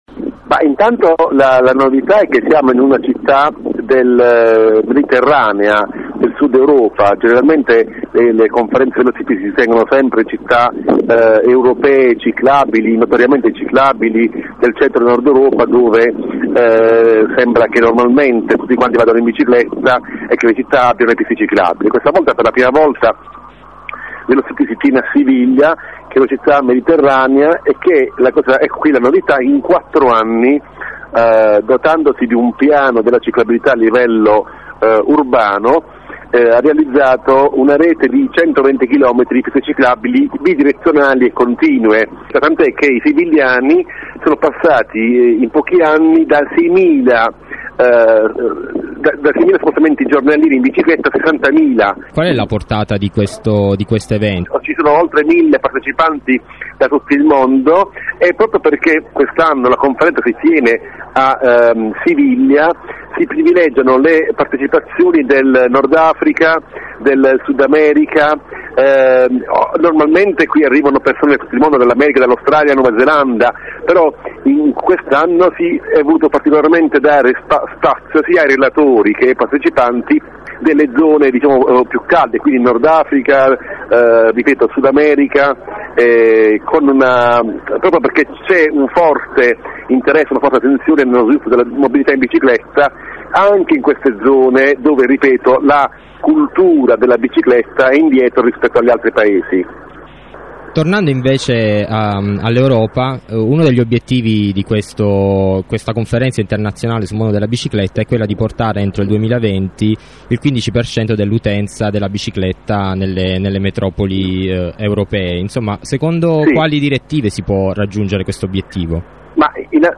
Posted by on 28/3/11 • Categorized as Interviste